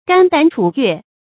肝膽楚越 注音： ㄍㄢ ㄉㄢˇ ㄔㄨˇ ㄩㄝˋ 讀音讀法： 意思解釋： 肝膽：比喻關系密切；楚越：春秋時兩個諸侯國，雖土地相連，但關系不好。